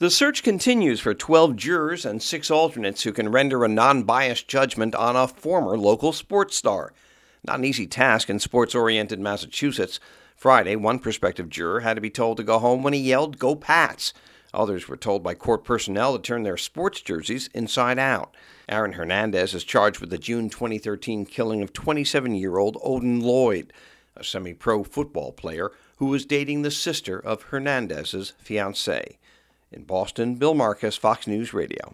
FOX NEWS RADIO’S